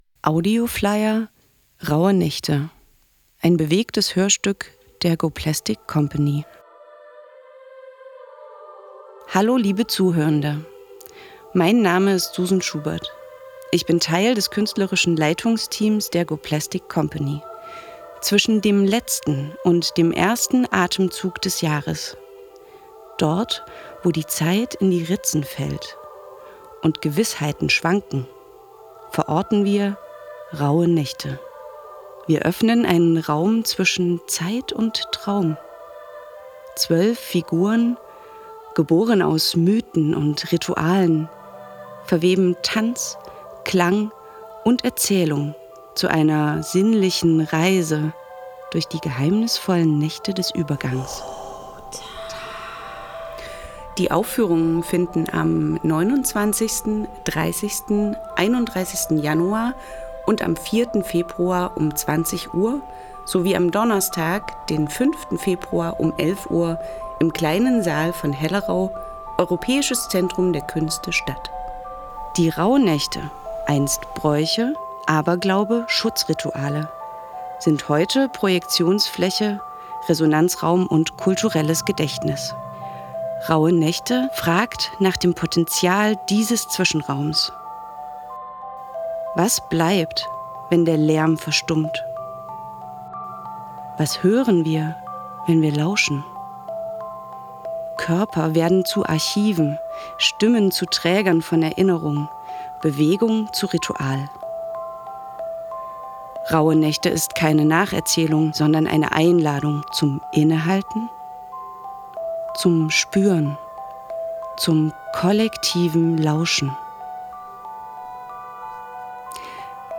RAUE-NAeCHTE-Audioflyer-Dresden-Hellerau.mp3